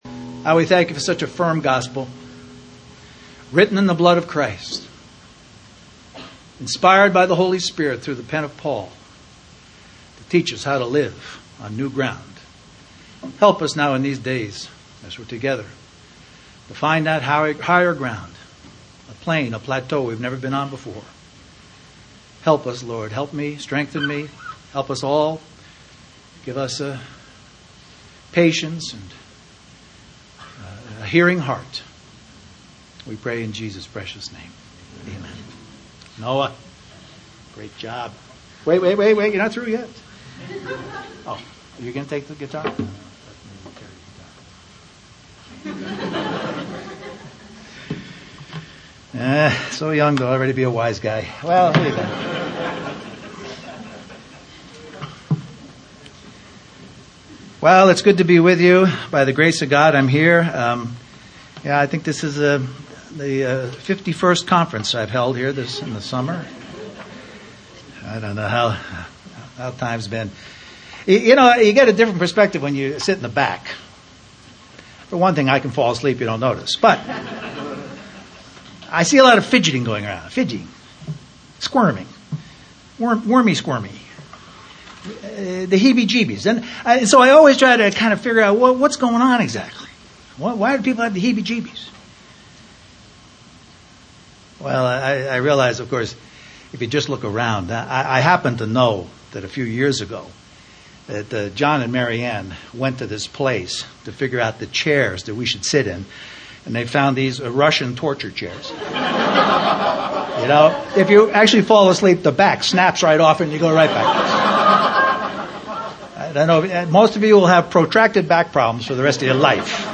Toronto Summer Youth Conference